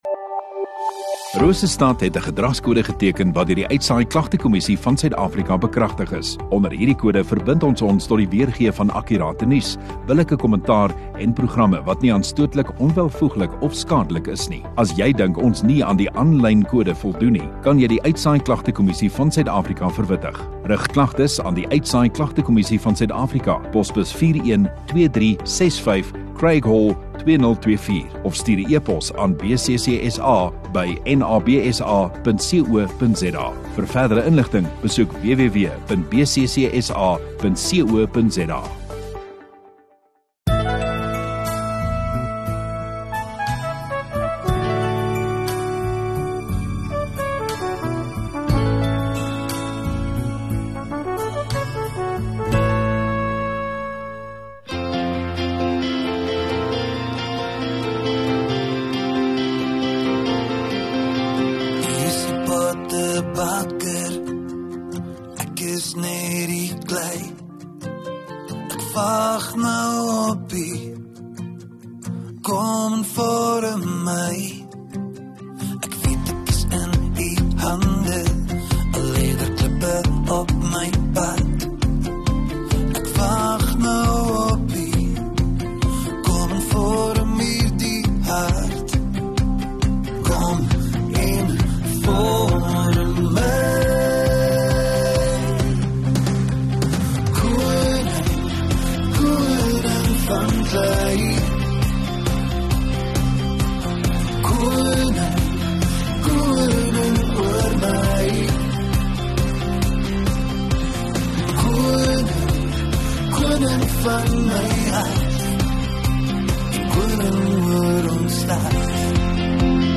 8 Jan Woensdag Oggenddiens